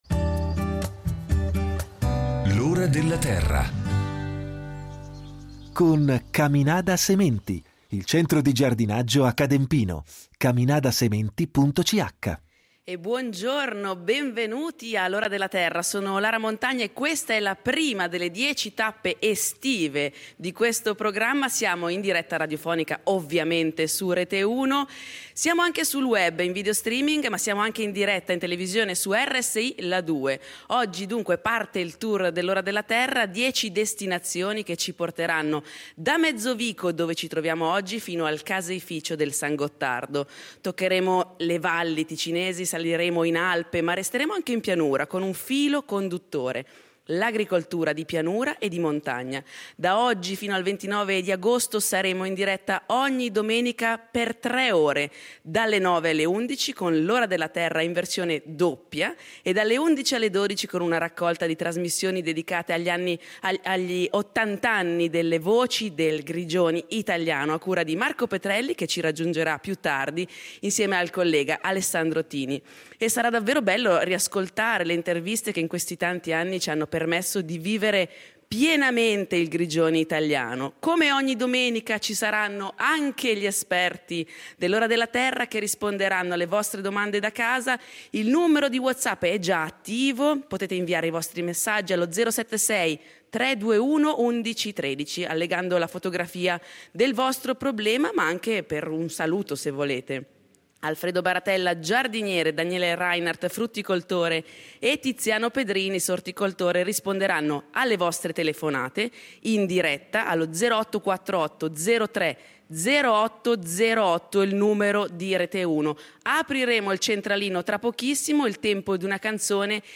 La prima puntata andrà in onda dell’alta Valle del Vedeggio, a Mezzovico, dalla cantina di affinamento dei formaggi DOP del Ticino. Un grande progetto di valorizzazione del formaggio d’alpe ticinese, conservato a maturare presso la Cetra Alimentari .